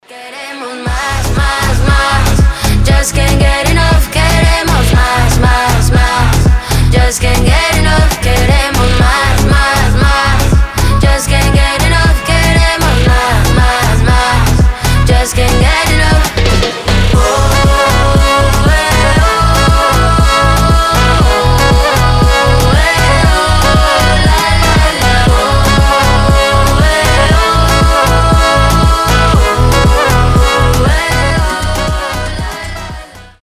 танцевальные
реггетон
латиноамериканские , зажигательные